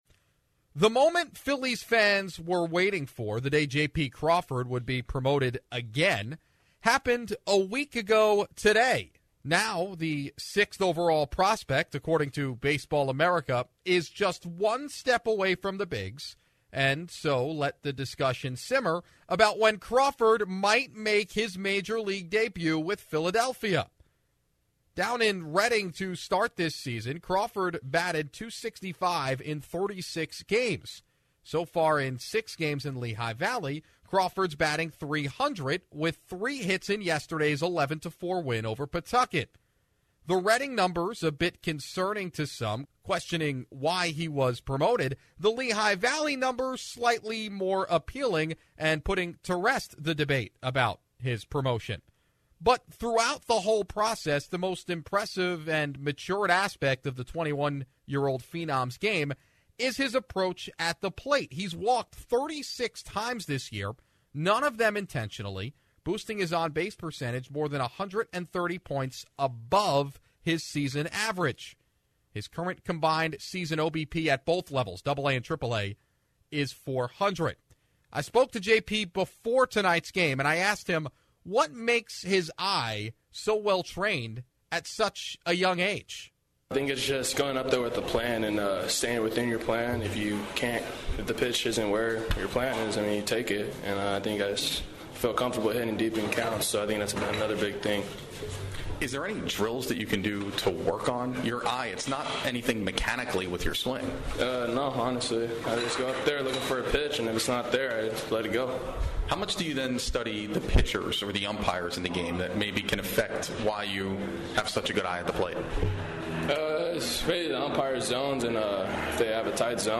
INTERVIEW with Pigs SS J.P. Crawford